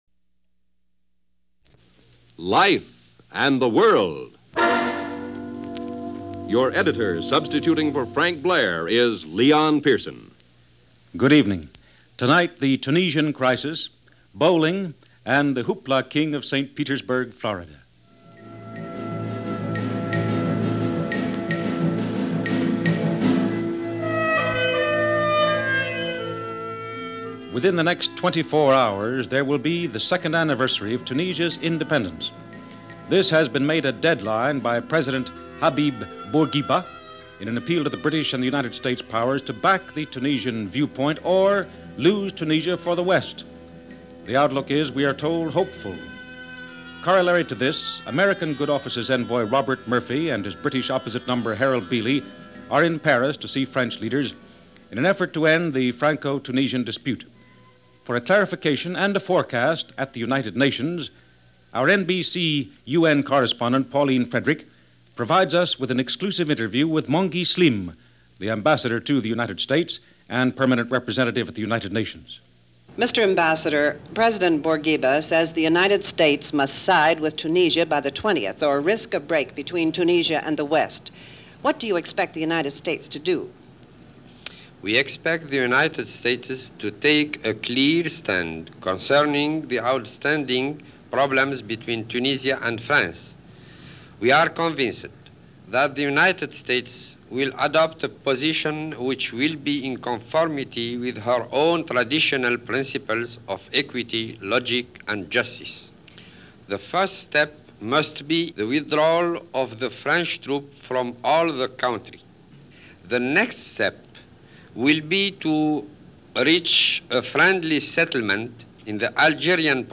View From Tunisia - View From The Bowling Alley - March 18, 1958 - NBC Radio News - Life And The World.